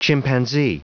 Prononciation du mot chimpanzee en anglais (fichier audio)
Prononciation du mot : chimpanzee